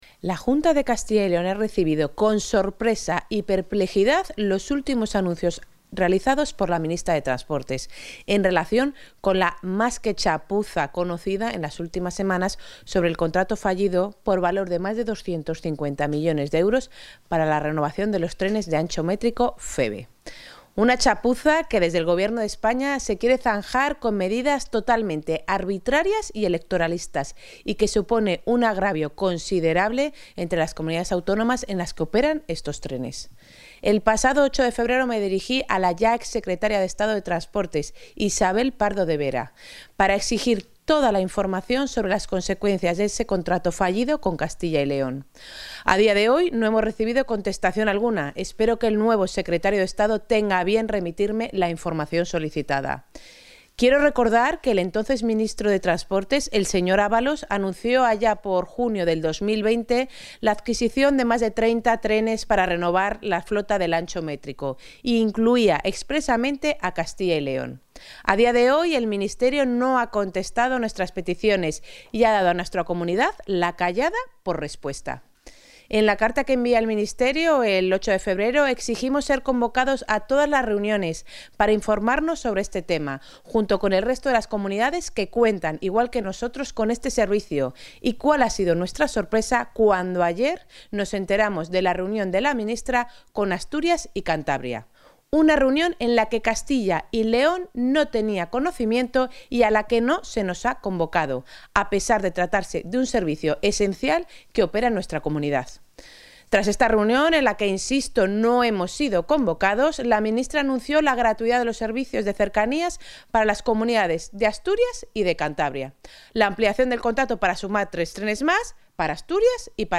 Valoración de la consejera de Movilidad y Transformación Digital sobre el contrato fallido para la renovación de trenes de ancho métrico